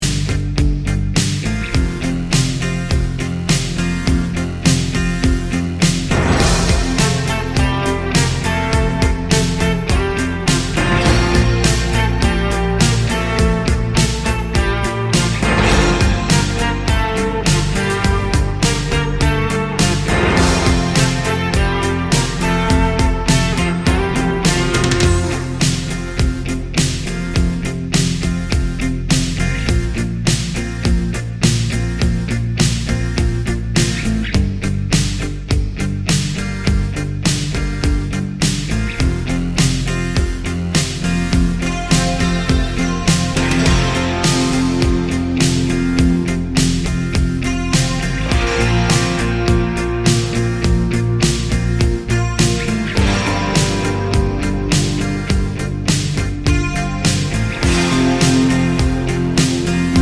no Backing Vocals Soft Rock 4:09 Buy £1.50